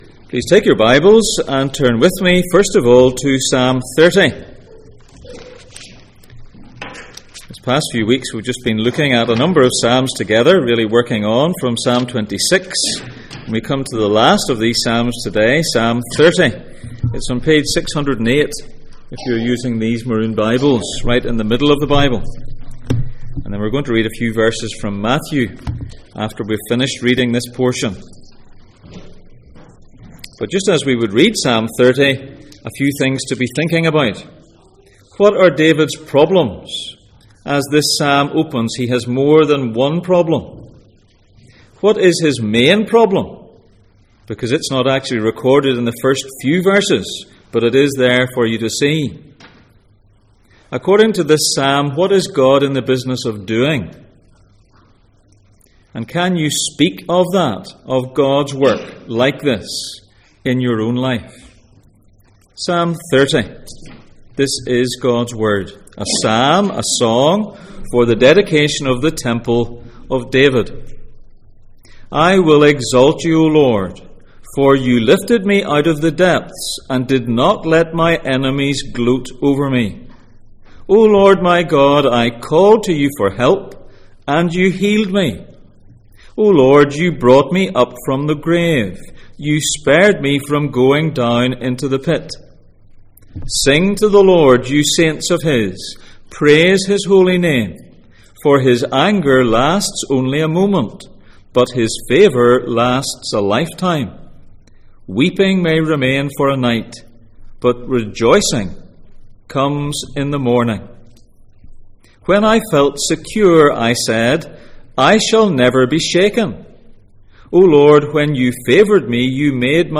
Psalms Passage: Psalm 30:1-12, Matthew 22:1-14, Psalm 41:7-8, Luke 12:18-21 Service Type: Sunday Morning